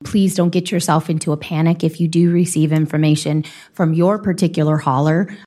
Council member Jihan Young says residents will get all the information they want about any changes before they are approved.